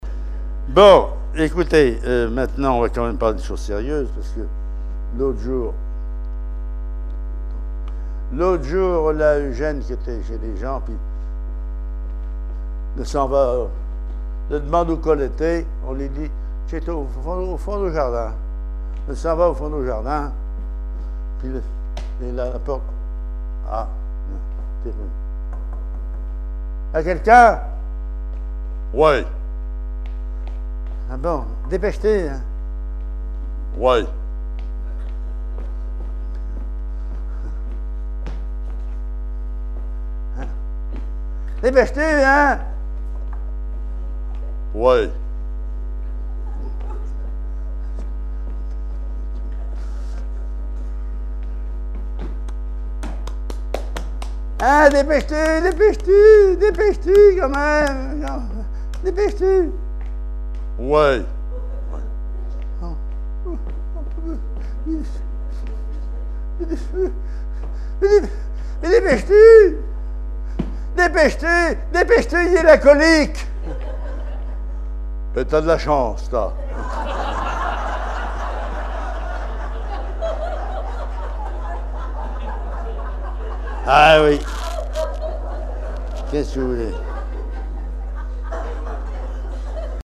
Patois local
Genre sketch
Catégorie Récit